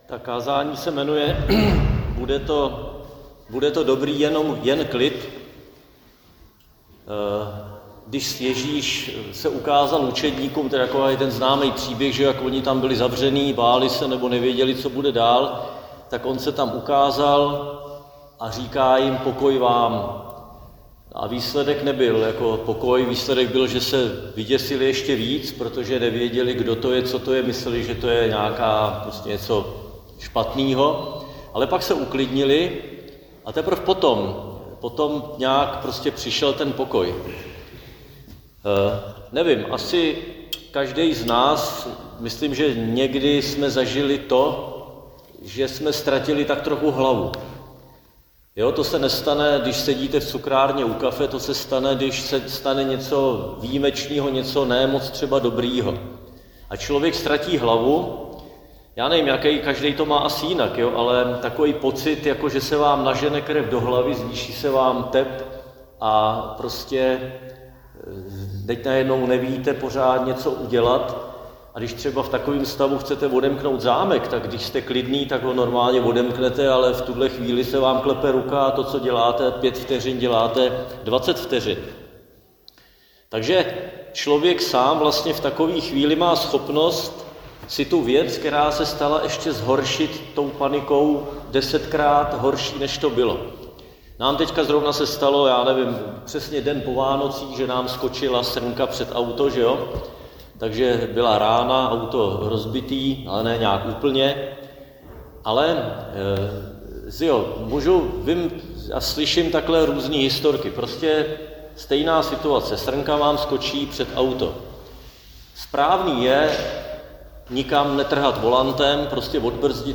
Křesťanské společenství Jičín - Kázání 8.3.2026